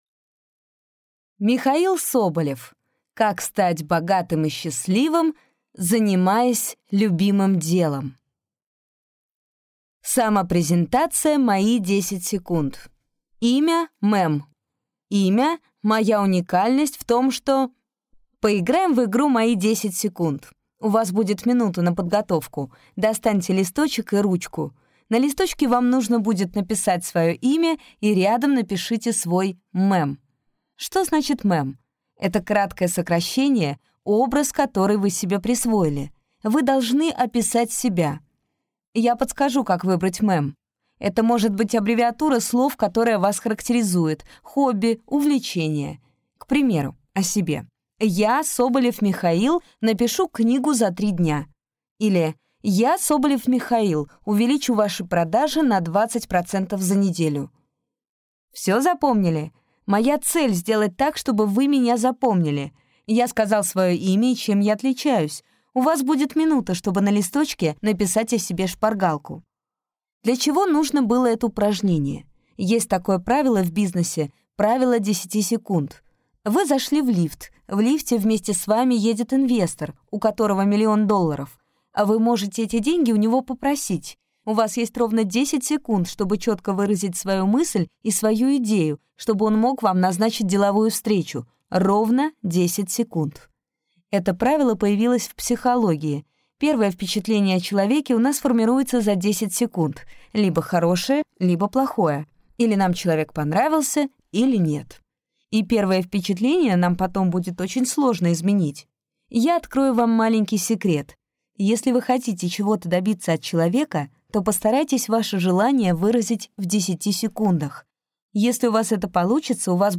Аудиокнига Как стать богатым и счастливым, занимаясь любимым делом?